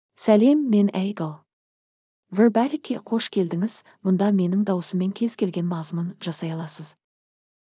Aigul — Female Kazakh (Kazakhstan) AI Voice | TTS, Voice Cloning & Video | Verbatik AI
Aigul is a female AI voice for Kazakh (Kazakhstan).
Voice sample
Listen to Aigul's female Kazakh voice.
Aigul delivers clear pronunciation with authentic Kazakhstan Kazakh intonation, making your content sound professionally produced.